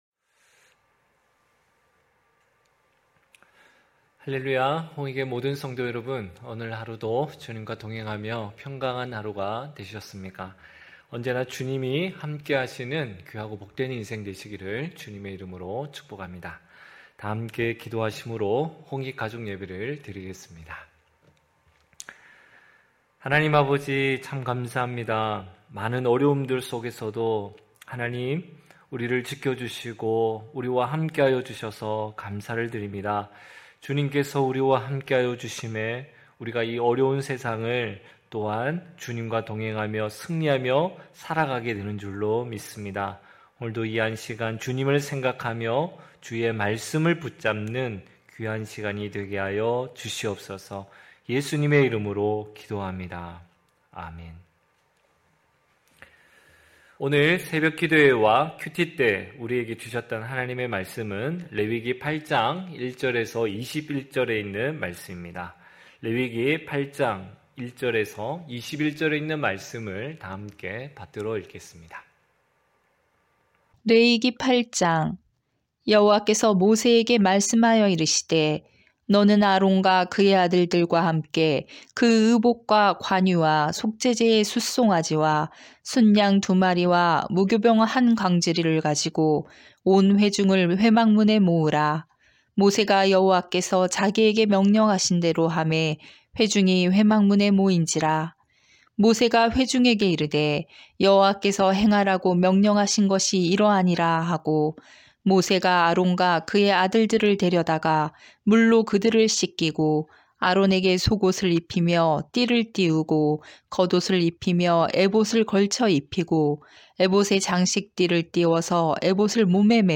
9시홍익가족예배(3월12일).mp3